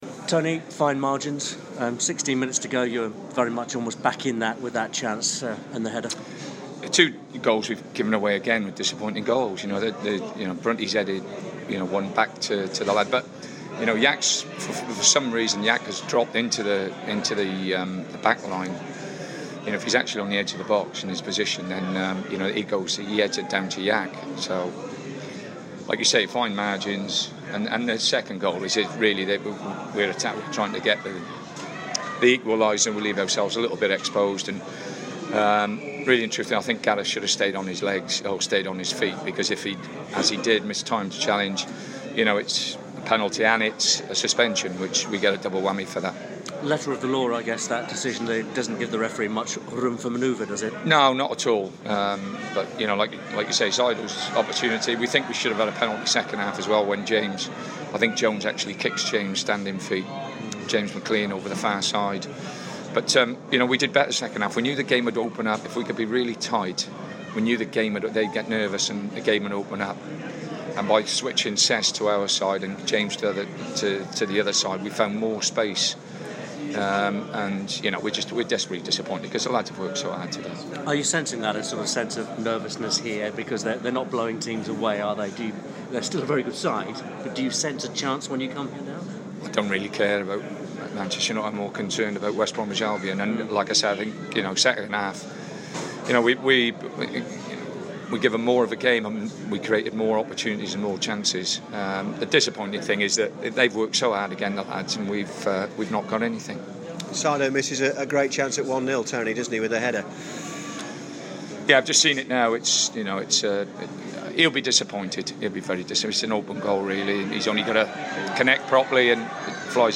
Albion boss Tony Pulis speaks to the media following his side's 2-0 defeat to Manchester United